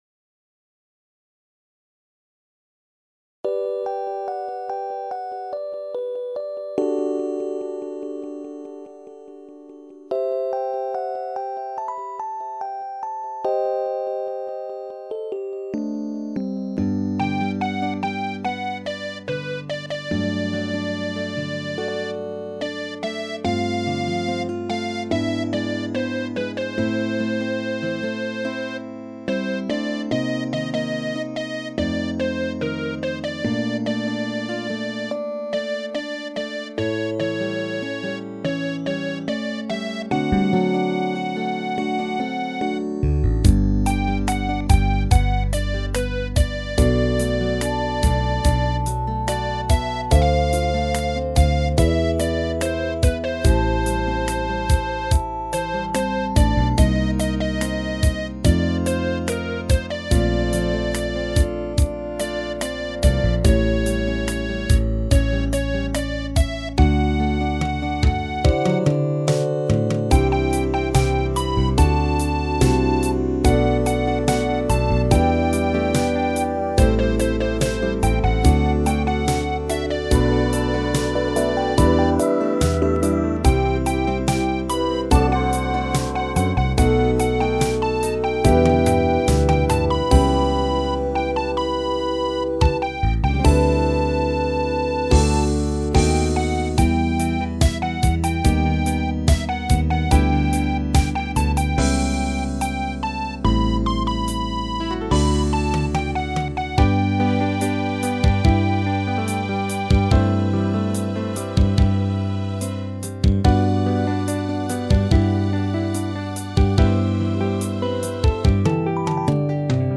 イントロのEPで余計な真似をしてトラックが結局ギリギリ。
ギターはやっぱナイロンとクリーンの中間のまともなAGの音色が欲しい。
スチールギターも全然スチールギターじゃないし。
立ち上がりの遅いスイープかメタリックで対応するしかない。